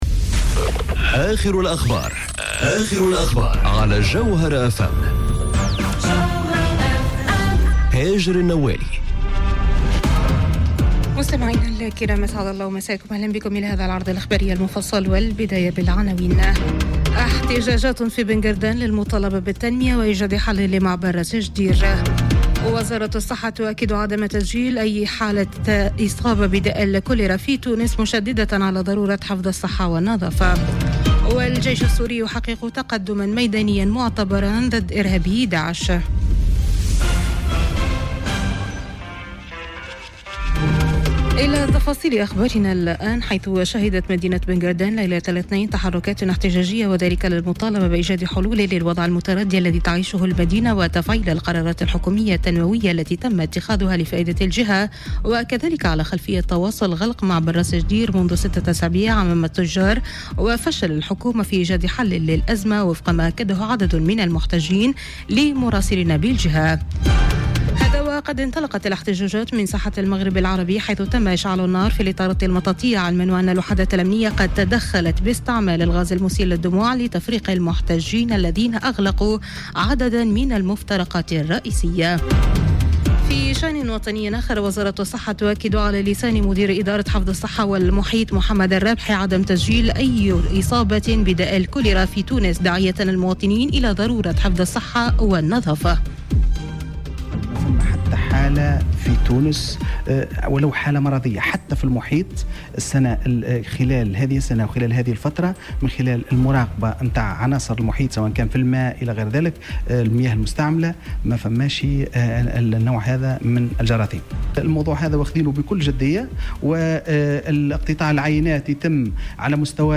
نشرة أخبار منتصف الليل ليوم الثلاثاء 28 أوت 2018